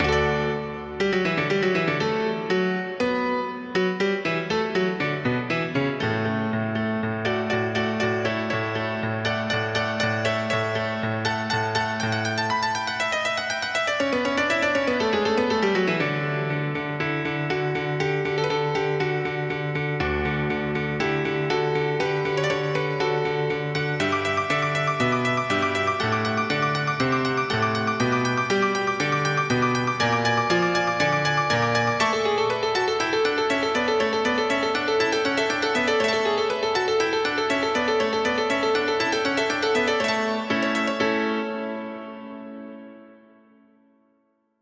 música clásica
sonata